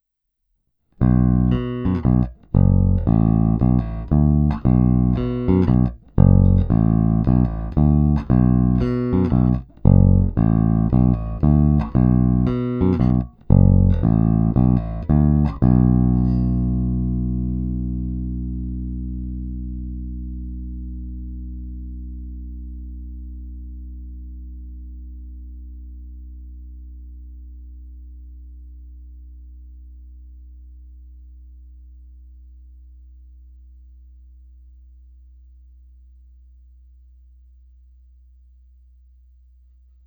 Polohy 1-5 jsou postupně následovně: obě cívky snímače u krku, vnější cívky obou snímačů, všechny čtyři cívky, vnitřní cívky obou snímačů a obě cívky kobylkového snímače.
V každé poloze je zvuk hodně konkrétní, pevný, zvonivý.
Není-li uvedeno jinak, následující nahrávky jsou provedeny rovnou do zvukové karty, jen normalizovány, jinak ponechány bez úprav.
Hráno vždy mezi snímači, korekce ponechány ve střední poloze.